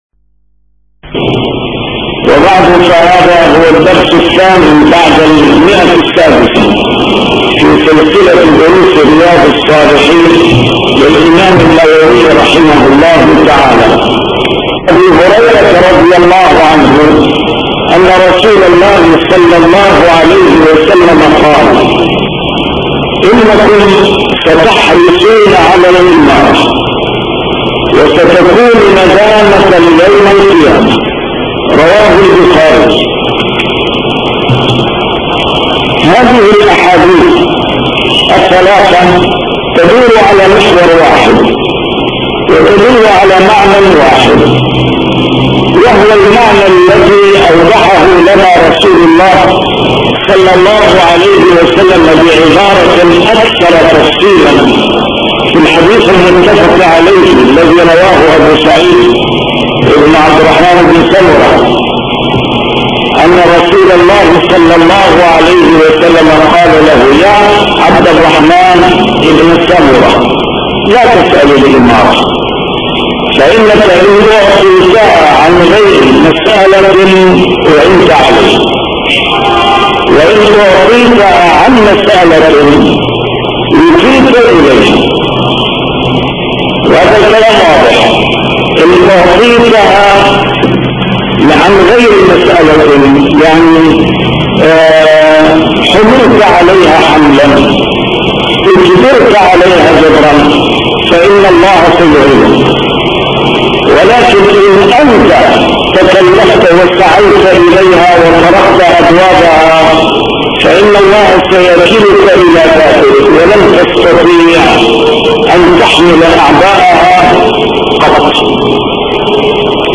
A MARTYR SCHOLAR: IMAM MUHAMMAD SAEED RAMADAN AL-BOUTI - الدروس العلمية - شرح كتاب رياض الصالحين - 602- شرح رياض الصالحين: النهي عن سؤال الإمارة